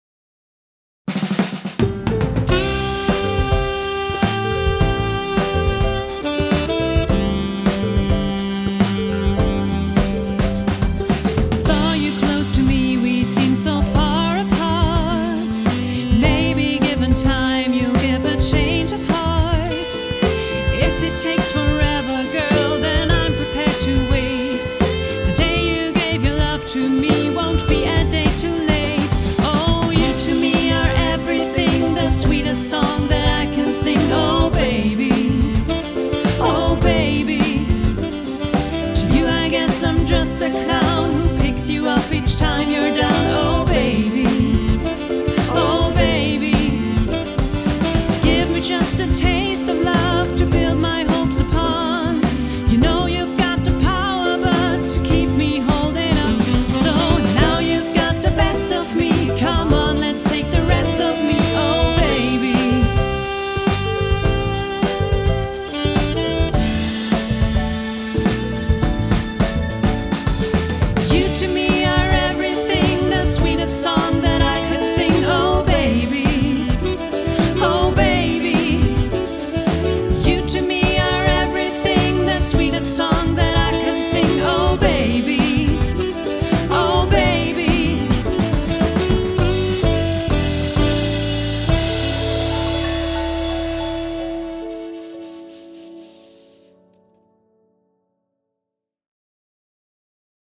Quintett